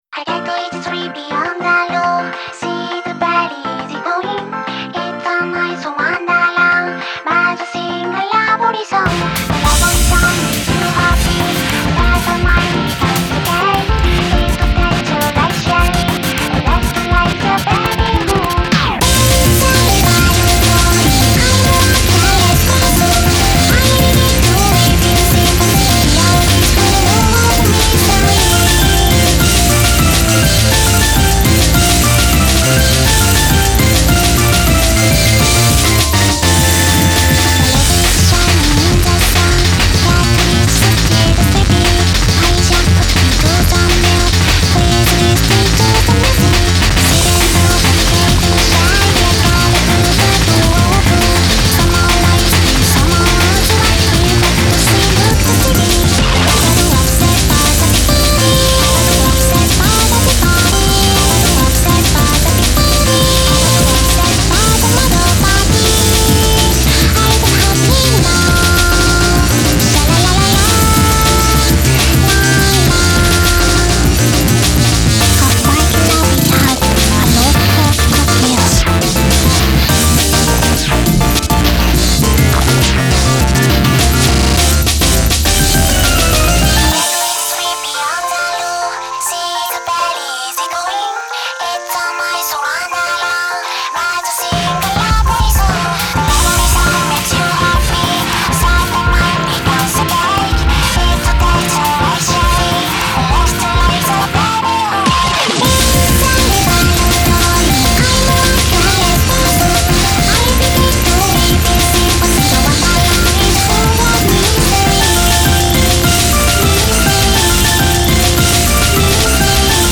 BPM103-205
Audio QualityPerfect (High Quality)
Genre: BREAK CORE.